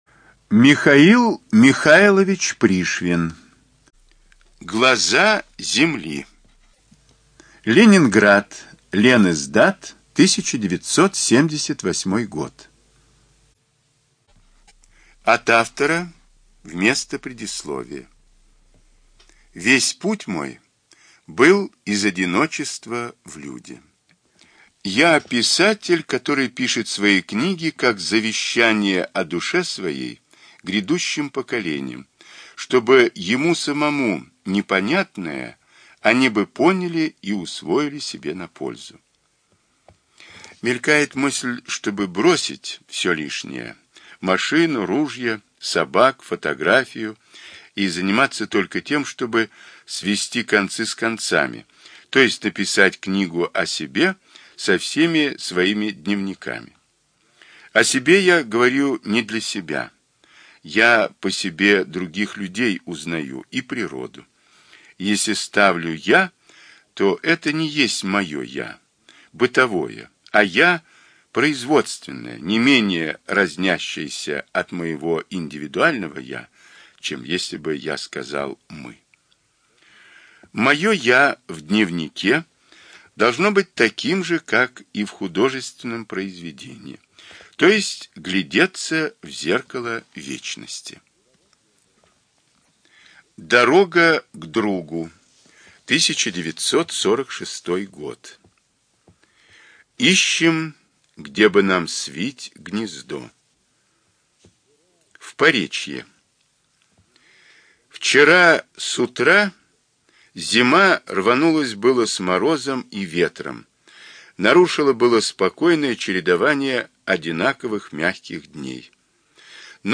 ЖанрДетская литература
Студия звукозаписиЛогосвос